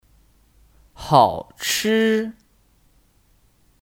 好吃 (Hǎochī 好吃)